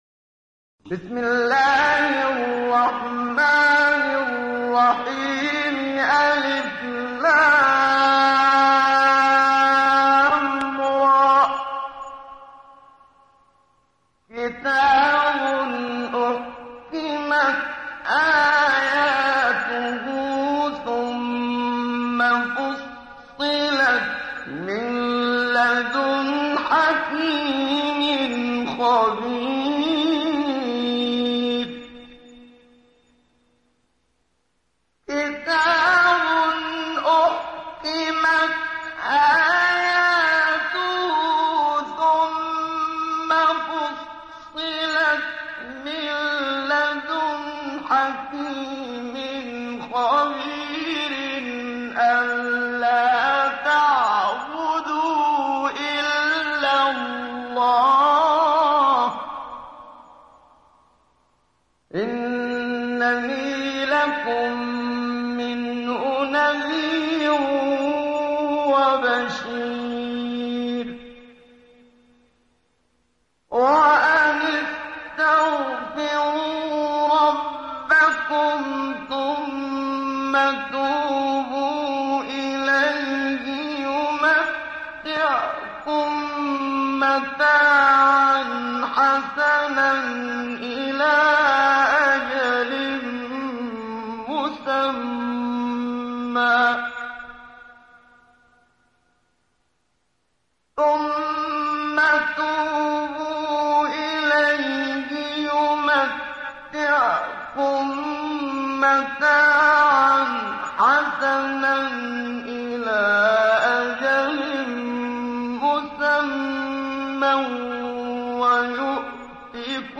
ডাউনলোড সূরা হূদ Muhammad Siddiq Minshawi Mujawwad